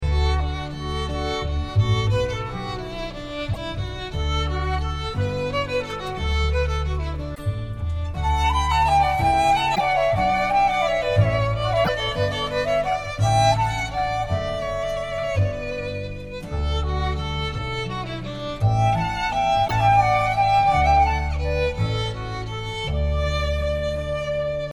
instrumental and vocal folk music of Ireland